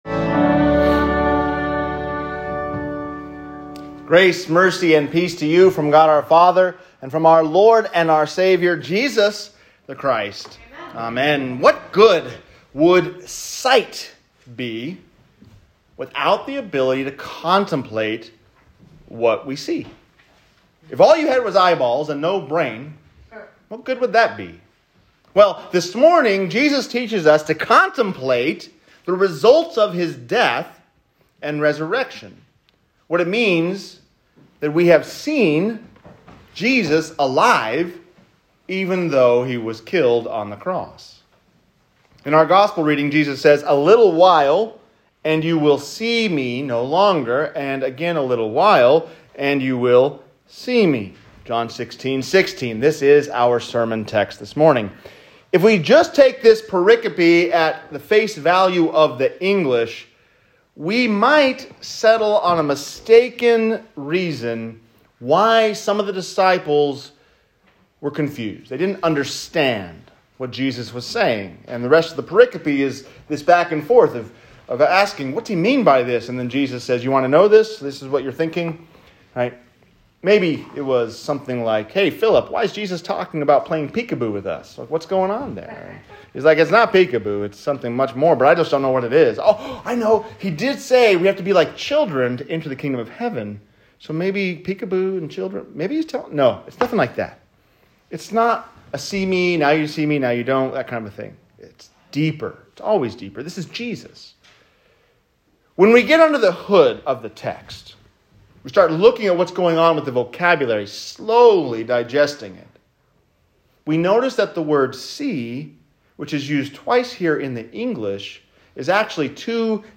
You Will See Me | Sermon